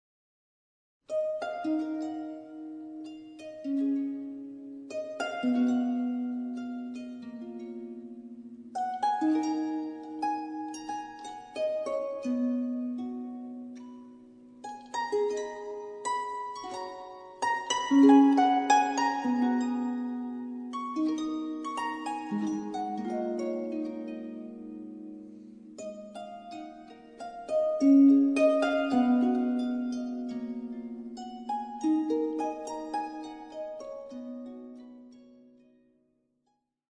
Harpe, Violoncelle, Flûtes à bec et Viole de Gambe